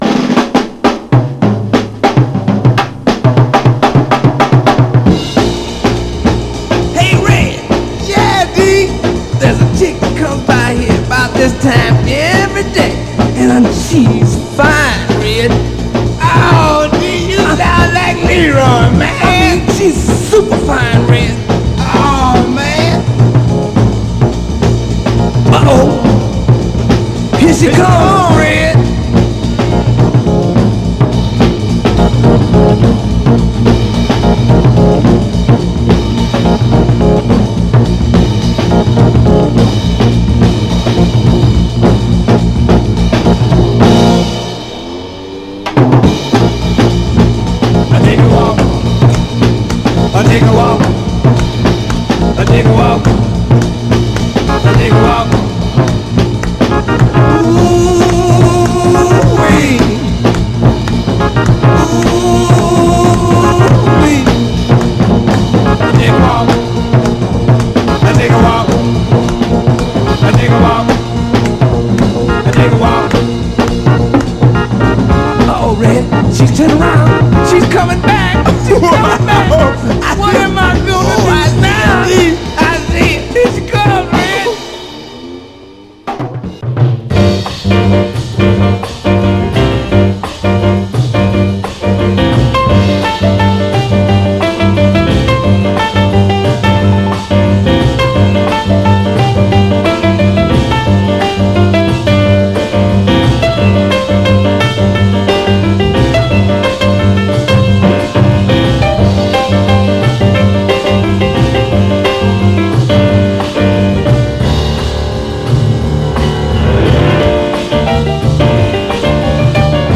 両面共にヒップでクールなシカゴ産ソウル・ジャズの名曲です！
※試聴音源は実際にお送りする商品から録音したものです※